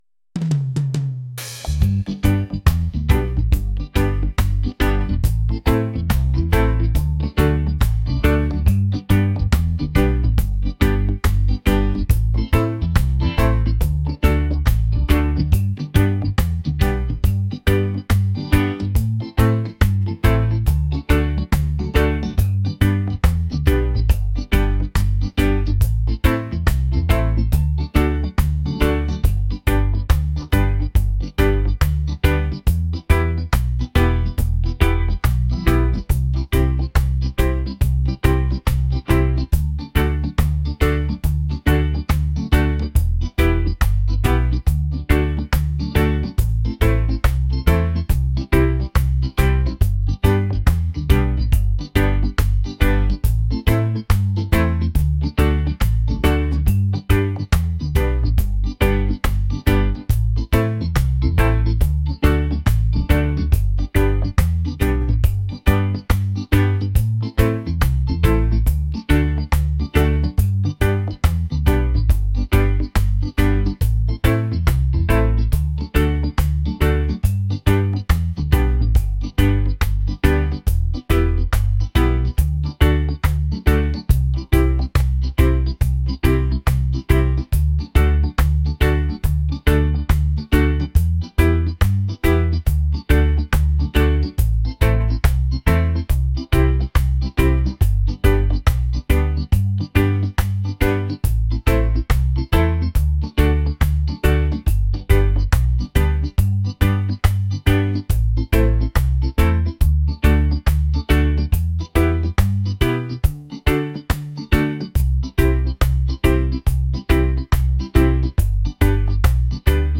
romantic | reggae | laid-back